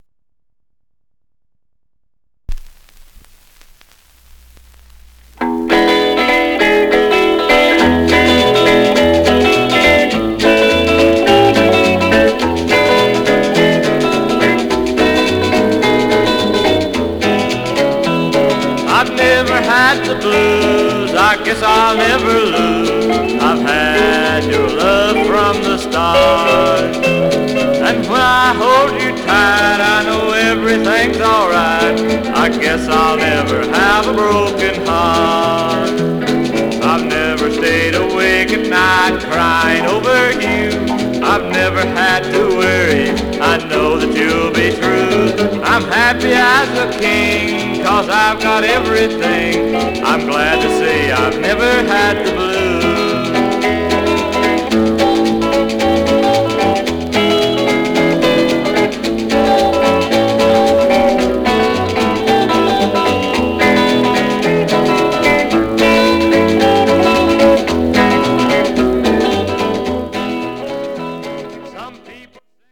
Some surface noise/wear Stereo/mono Mono
Rockabilly